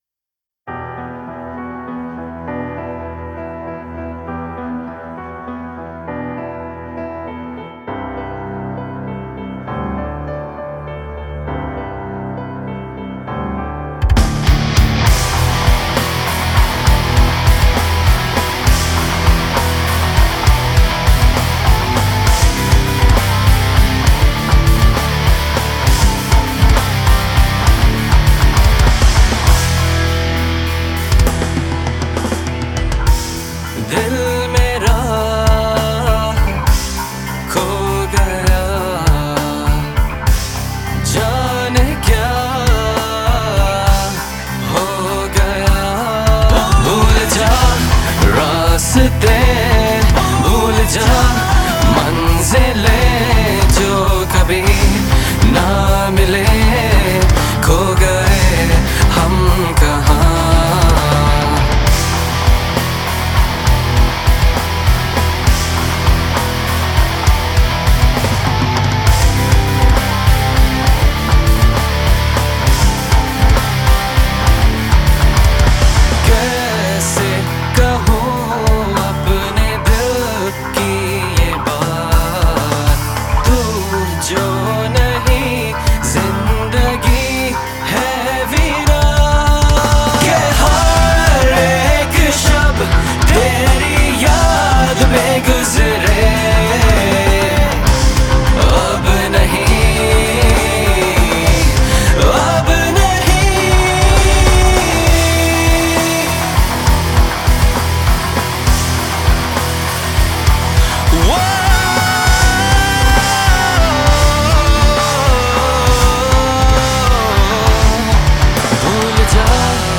progressive rock genre song